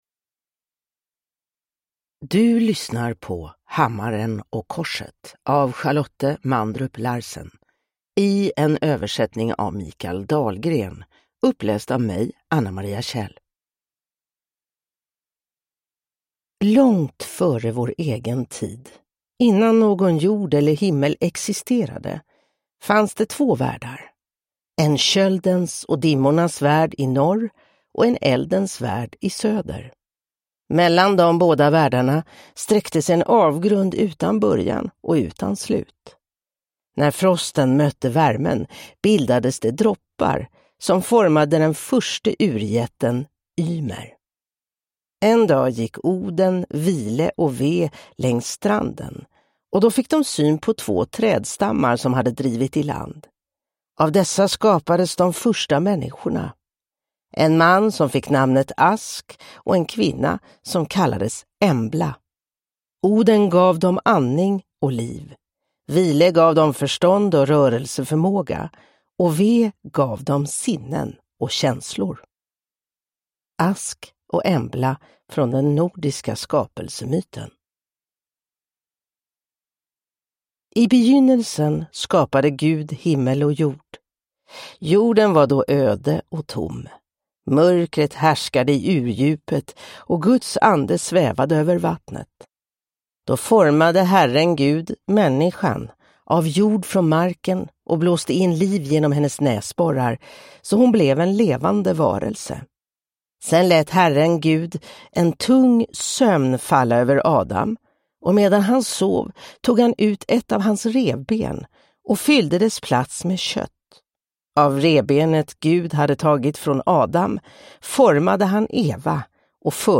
Hammaren och korset (ljudbok) av Charlotte Mandrup Larsen